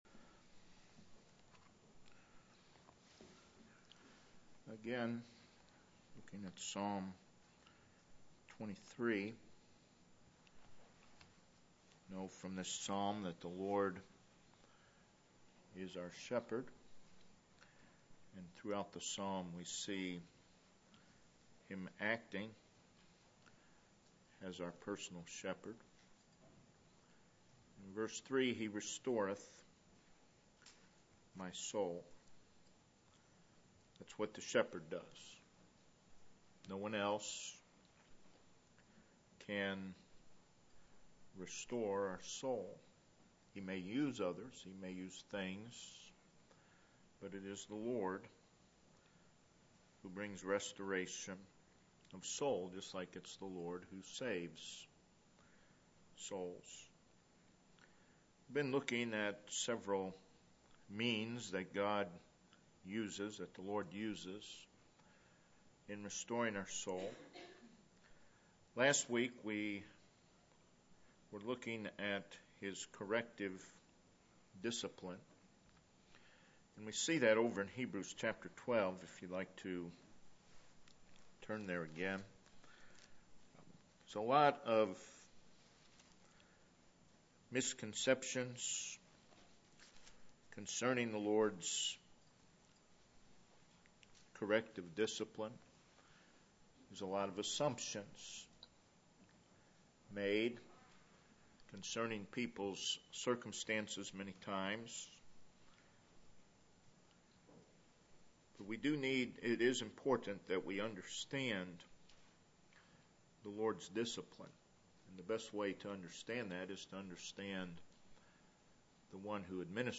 Home Baptist Church - 2017 Sunday School Services